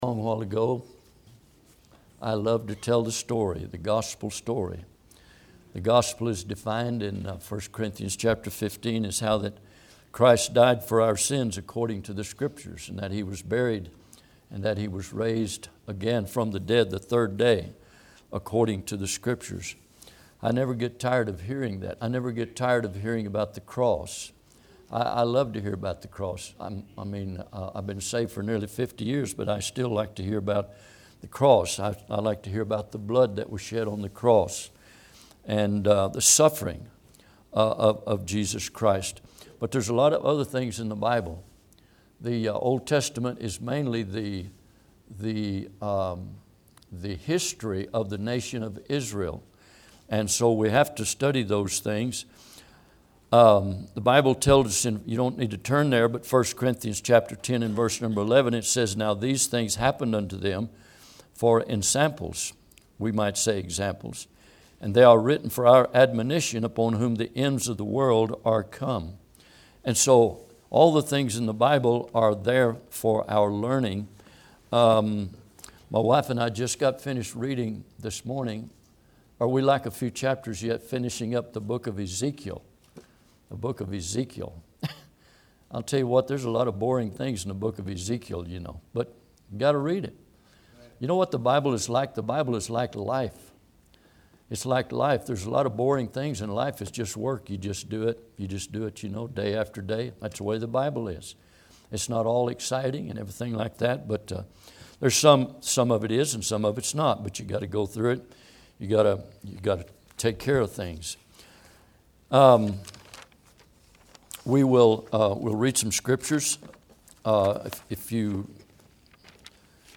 Service Type: Midweek